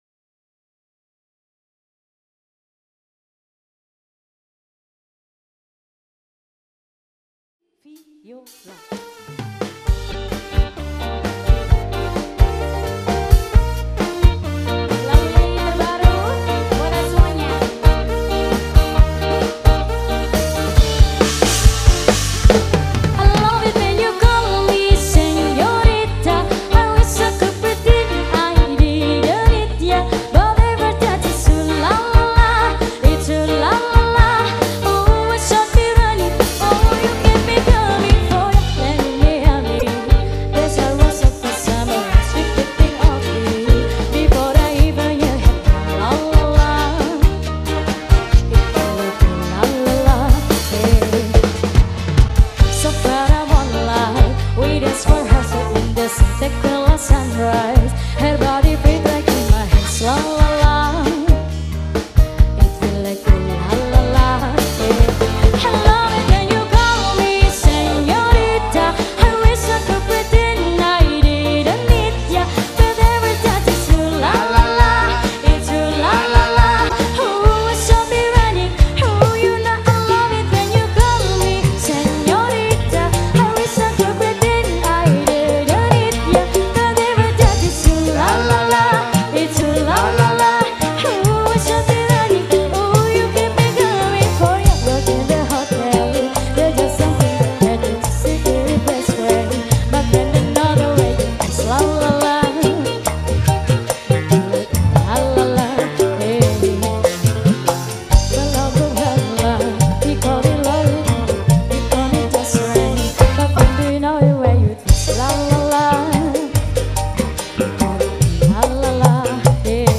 Jenre Musik                   : Dangdut, Pop, Koplo, Melayu